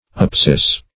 Apsis \Ap"sis\ ([a^]p"s[i^]s), n.; pl. Apsides